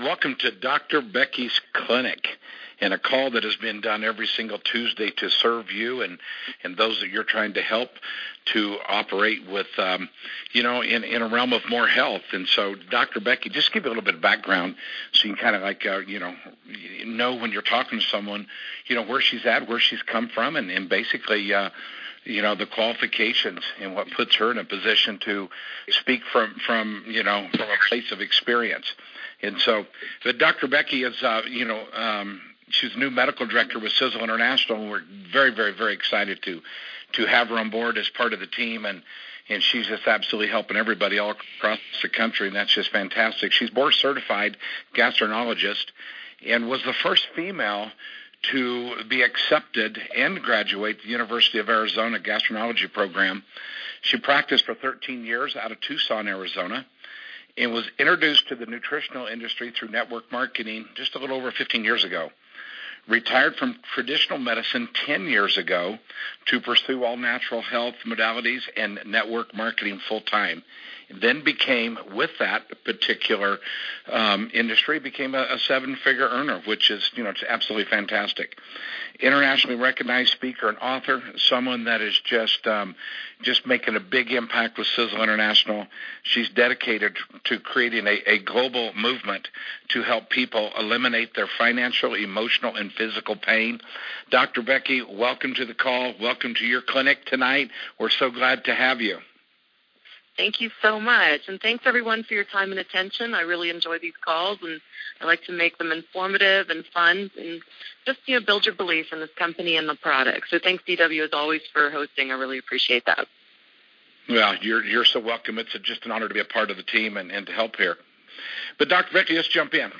(Click On Photo) Sisel product call about SiseLean.